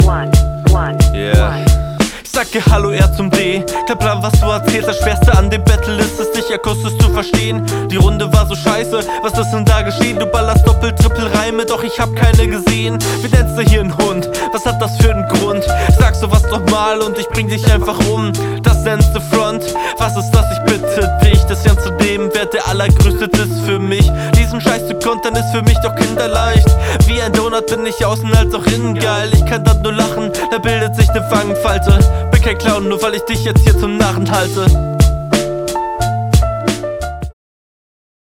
Flow eher weak aber geht klar, Mix auch, Punches gehen gut klar, Reime sind da